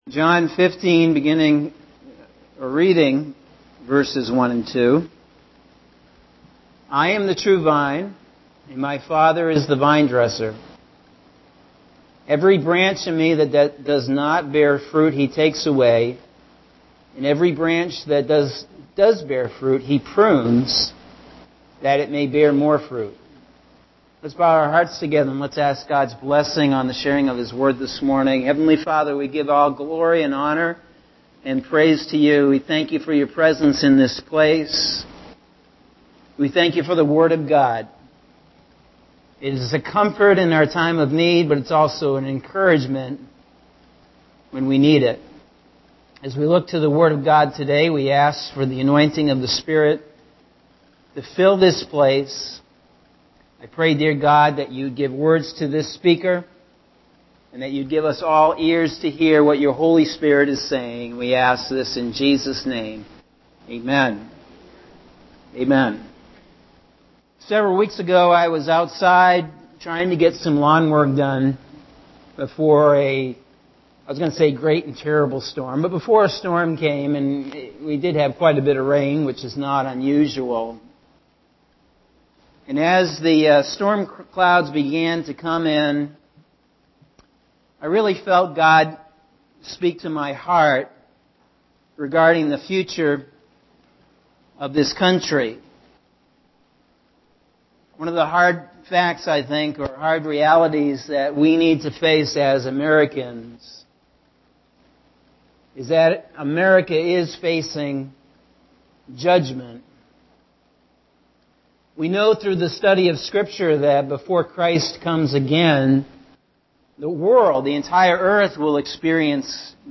Sunday June 23rd – AM Sermon – Norwich Assembly of God